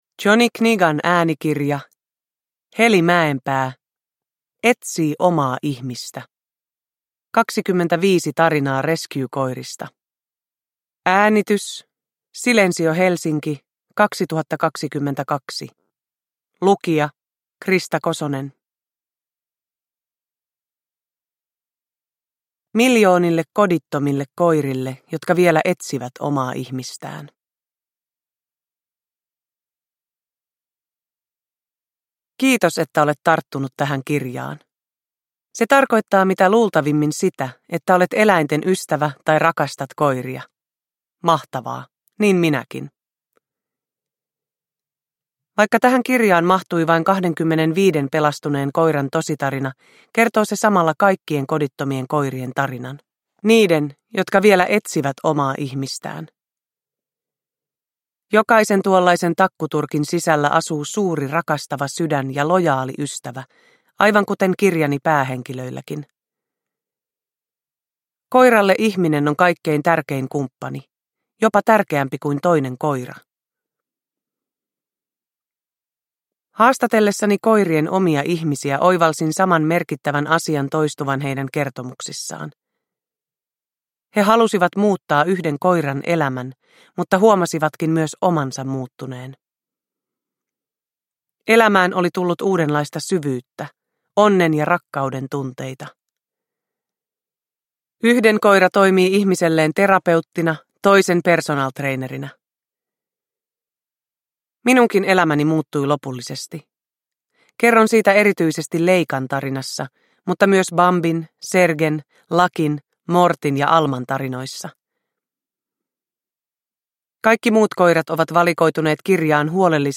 Etsii omaa ihmistä – Ljudbok – Laddas ner
Uppläsare: Krista Kosonen